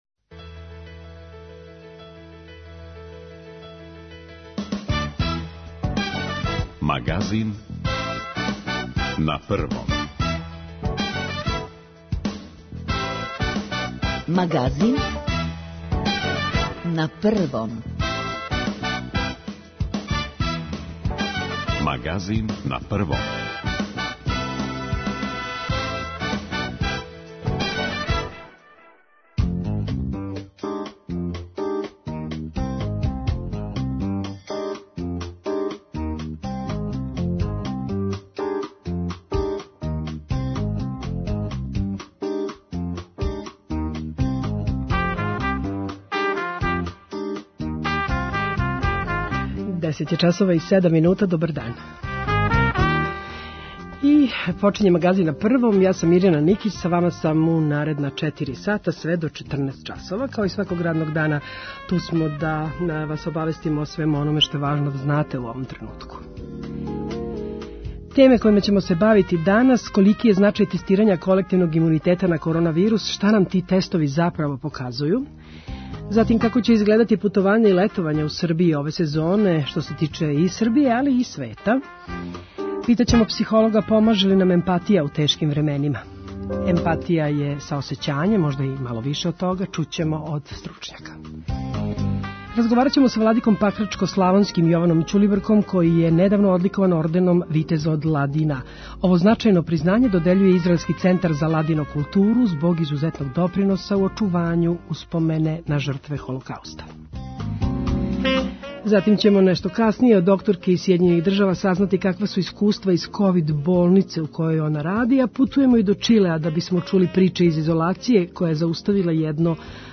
Разговараћемо са Владиком пакрачко-славонским, Јованом Ћулибрком, који је недавно одликован орденом "Витез од Ладина".